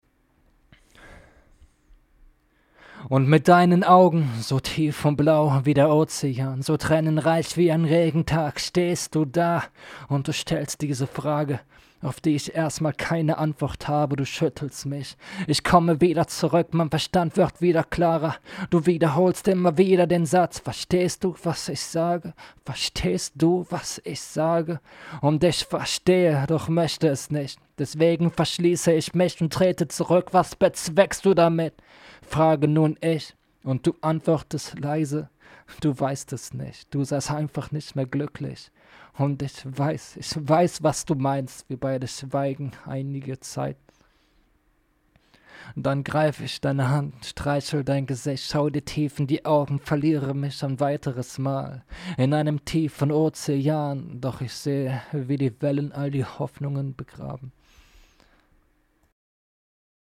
Schlechte Aufnahmequalität trotz akzeptablen Equipments (Shure 7 SMB)
Also ich habe nun noch mal ein Acapella aufgenommen, und war dabei sehr nah am Mikrofon, ebenfalls habe ich einen Poppschutz verwendet. Der Preamp war wieder voll aufgedreht und ich habe es im DAW lauter gestellt. Doch ich habe tatsächlich das Gefühl, dass meine Stimme nicht besonders mit dem Mikrofon harmoniert.